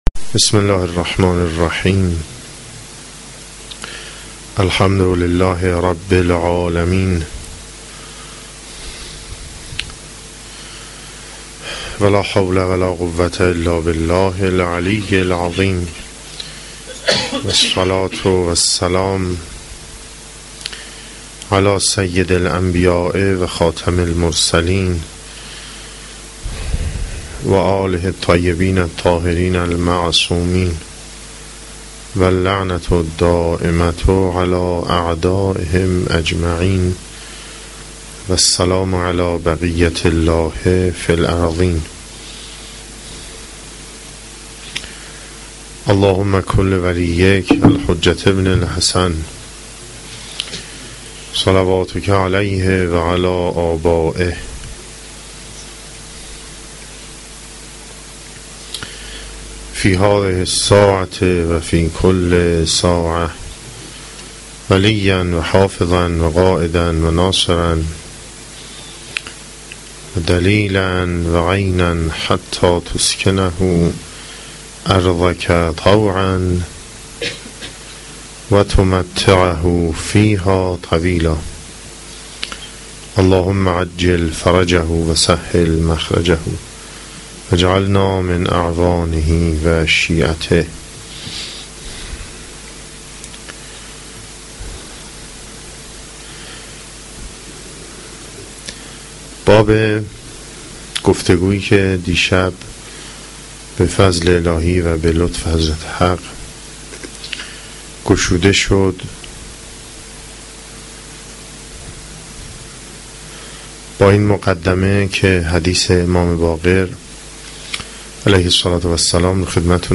سخنرانی شب دوم